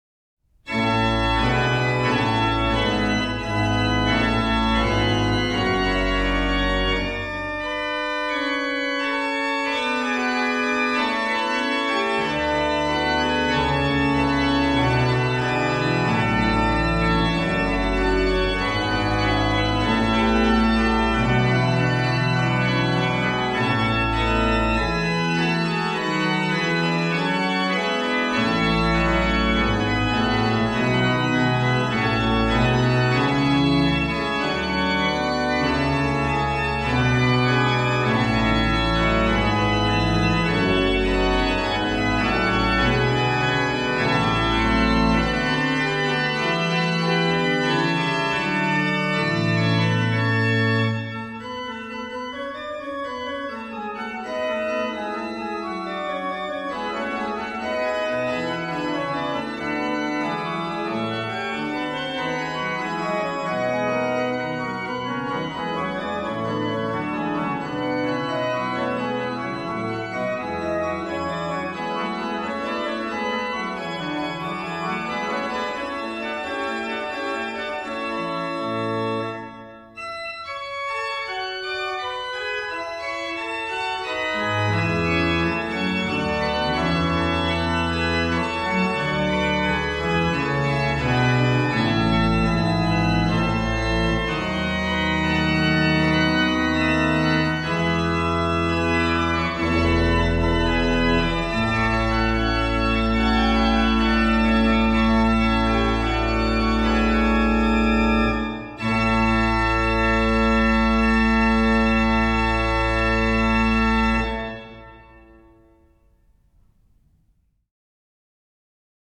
Registration   OW: Oct8, Oct4, Mix
Ped: Pr16, Oct4, Mix, Tr8
m. 20: BW: Ged8, Fl4, Oct2, 1 1/2